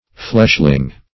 Fleshling \Flesh"ling\, n. A person devoted to fleshly things.
fleshling.mp3